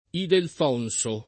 [ idelf 0 n S o ]